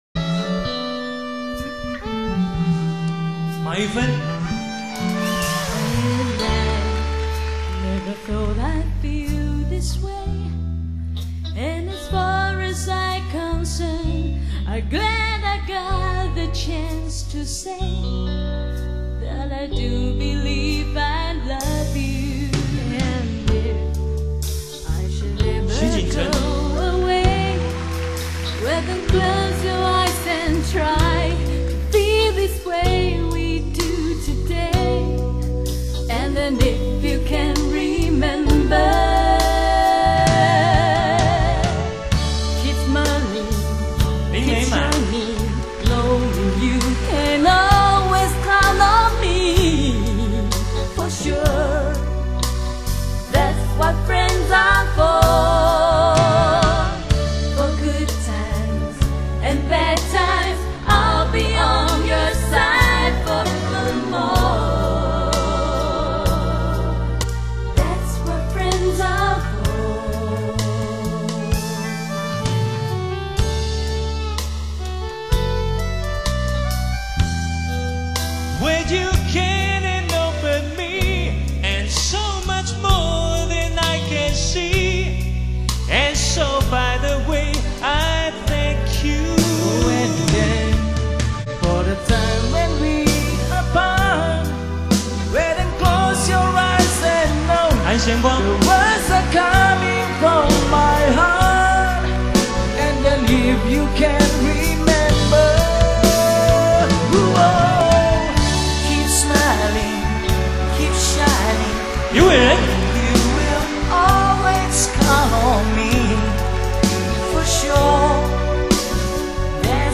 專輯類別：國語流行、絕版重現